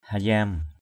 /ha-za:m/ (d.) bước = pas. step. yam klau hayam y’ k*~@ hy’ bước ba bước.
hayam.mp3